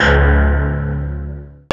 Index of /90_sSampleCDs/Roland L-CD701/BS _Synth Bass 1/BS _Wave Bass